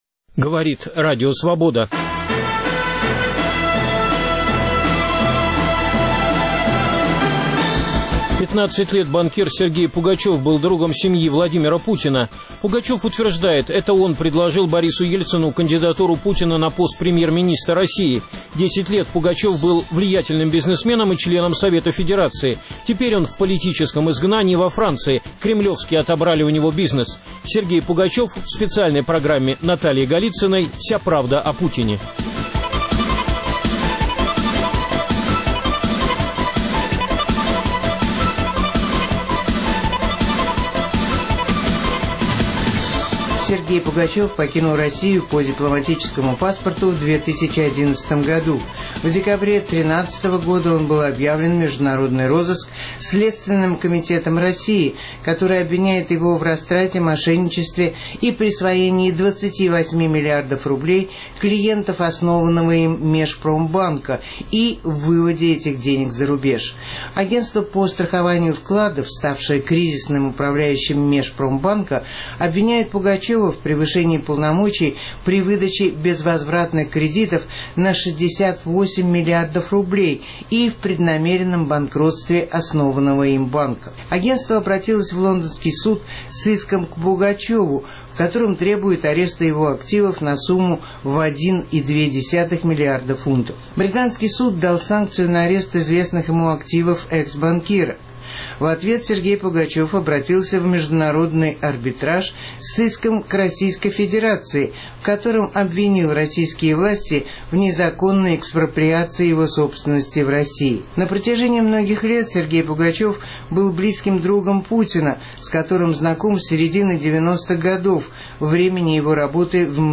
Опальный предприниматель Сергей Пугачев утверждает, что это он привел Владимира Путина к власти. В интервью